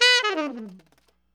TNR SHFL B4.wav